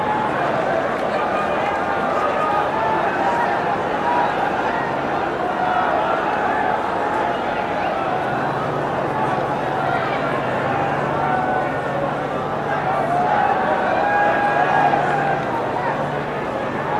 snd_audience_idle.ogg